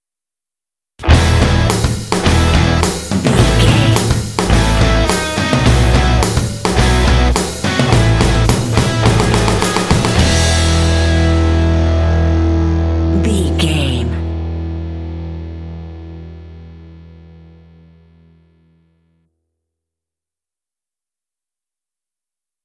This rock track is great for action and sports games.
Ionian/Major
motivational
energetic
lively
electric guitar
bass guitar
drums
alternative rock